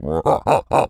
lizard_taunt_emote_03.wav